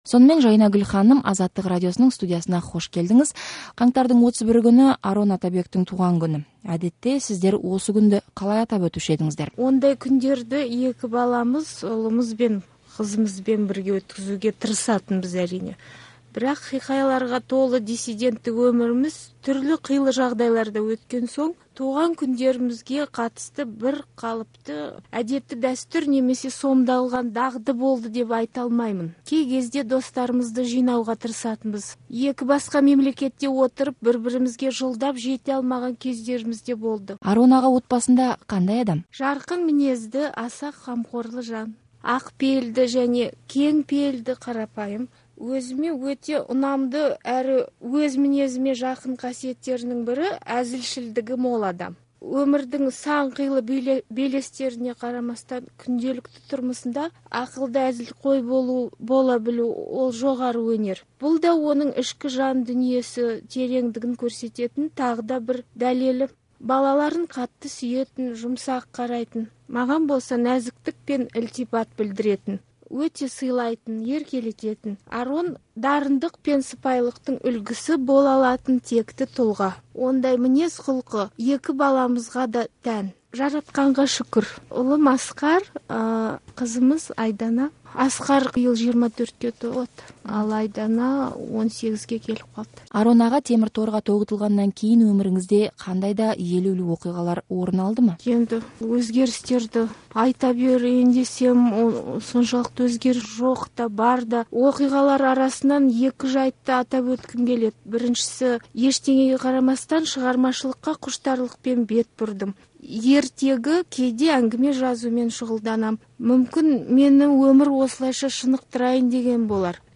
Арон Атабек туралы сұхбатты тыңдаңыз